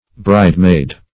Bridemaid \Bride"maid`\, n.